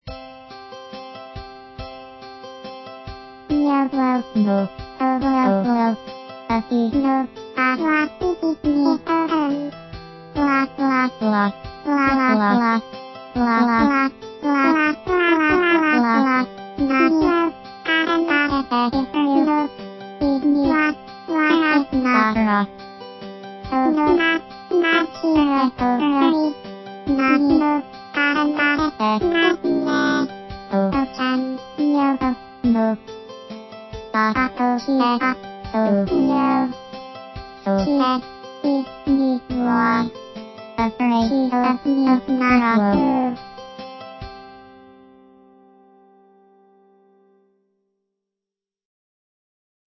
2011-05-20(Fri) チープに歌うLinux「妖音リナ」
「発声」には、以前も使ったfestivalをそのまま使う。